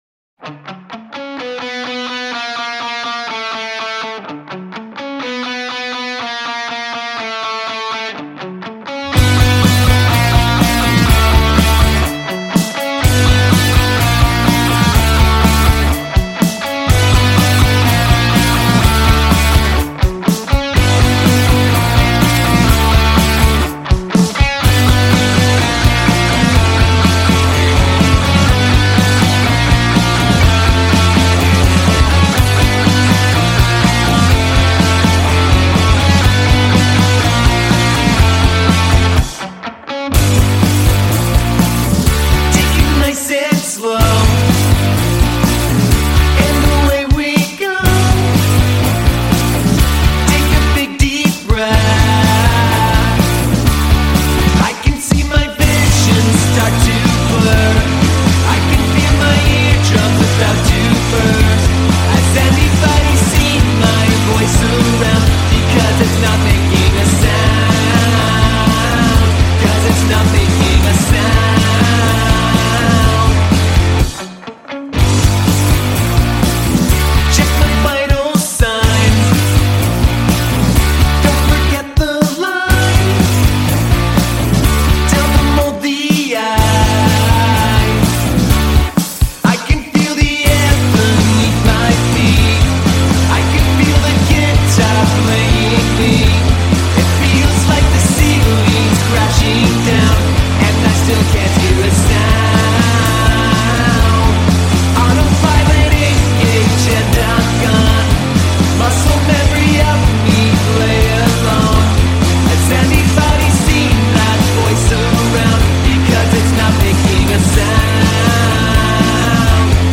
post-rock